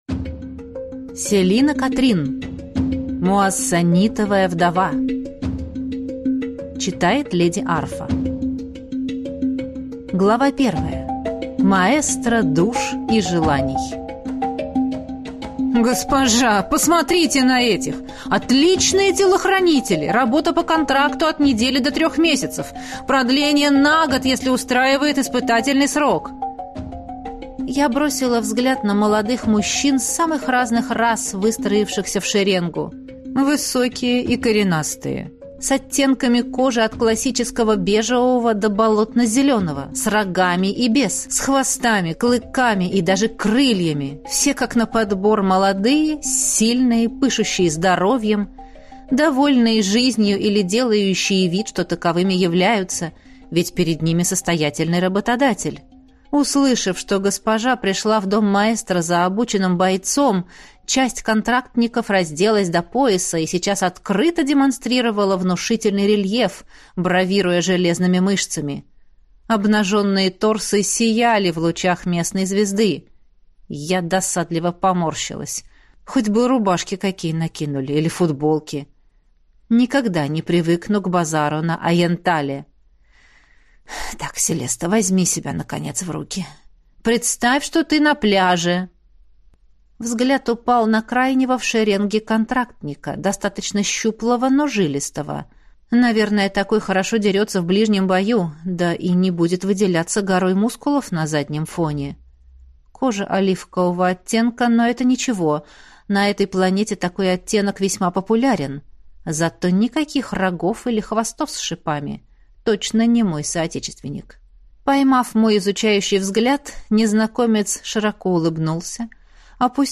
Аудиокнига Муассанитовая вдова | Библиотека аудиокниг